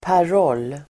Uttal: [par'ål:]